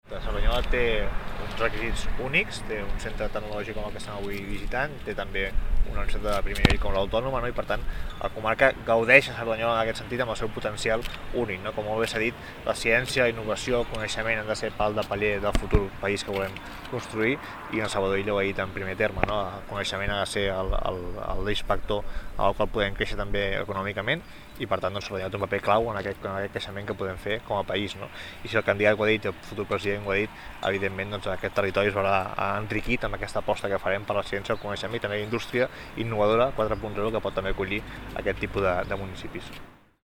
Declaracions de Pol Gibert. Innovació: